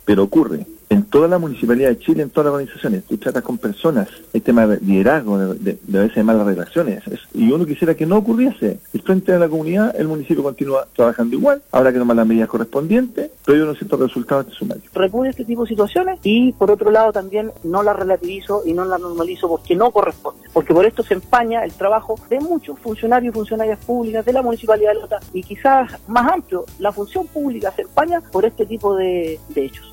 El alcalde, Patricio Marchant, señaló que este tipo de episodios ocurre en todas las municipalidades. Mientras que el concejal, Alejandro Cartes, pidió no normalizar la violencia.